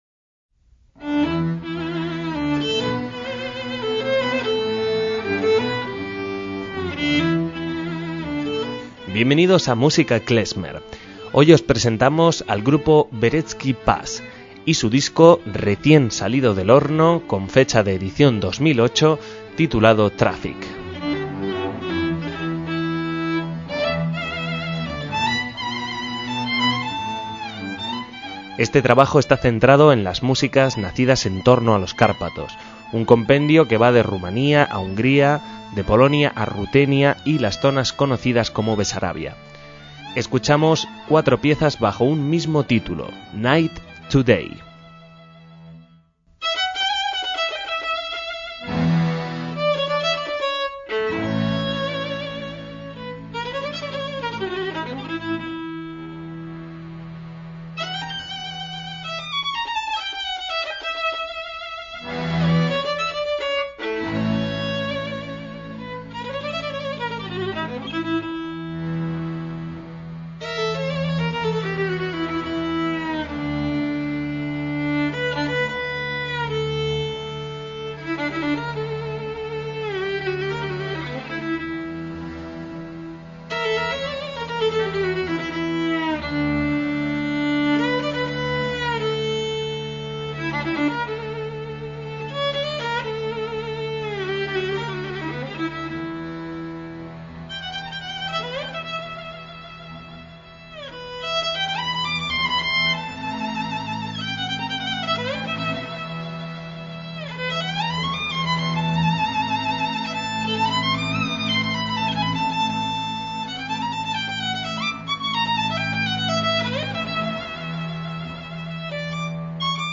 MÚSICA KLEZMER
violín y viola
acordeón, cimbalom y piano